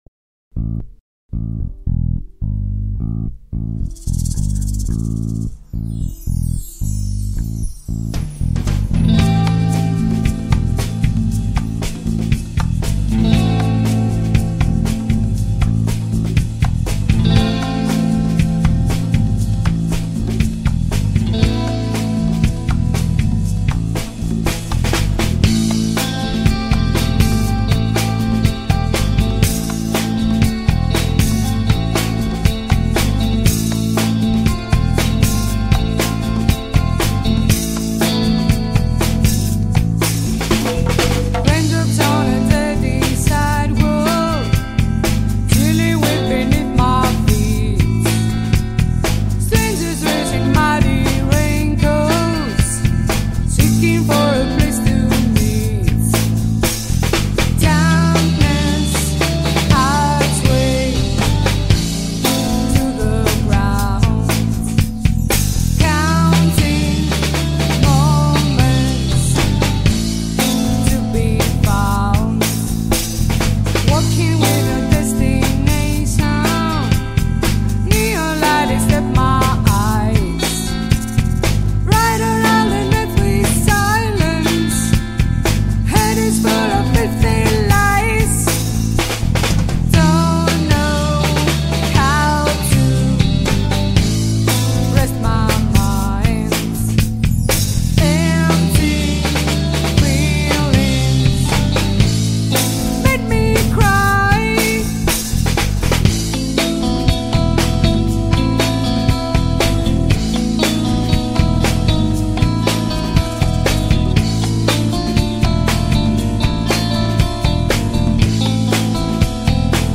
В итоге, это местная группа .